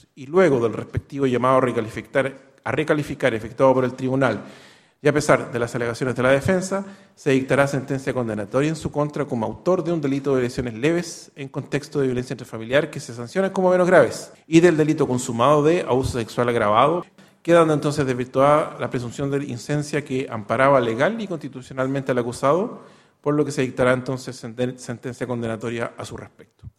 El veredicto condenatorio fue leído por el juez presidente, Wilfred Ziehlmann, quien indicó que “se dictará sentencia condenatoria en su contra como autor de un delito de lesiones leves en contexto de lesiones leves en contexto de violencia intrafamiliar, que se sancionan como menos graves, y del delito consumado de abuso sexual agravado”.